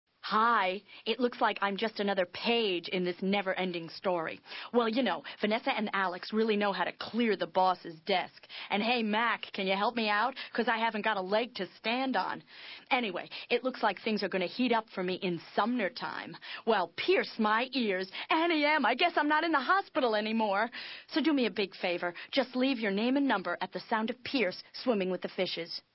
At The Sound Of The Beep... Soap Suds
Because the cassette these recordings were archived from was not properly labeled.